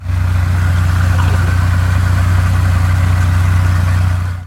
Propeller Plane Startup
A single-engine propeller plane sputtering to life with engine coughs and a steady idle
propeller-plane-startup.mp3